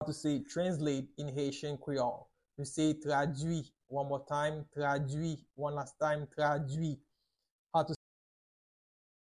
31.How-to-say-Translate-in-Haitian-Creole-–-Tradwi-with-pronunciation.mp3